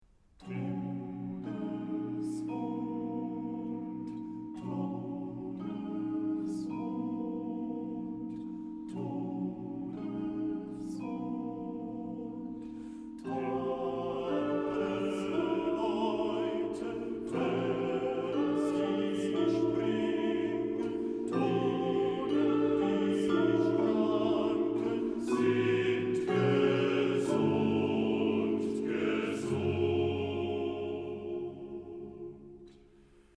für vierstimmigen Männerchor und 2 Schlagzeuger
Schlagzeug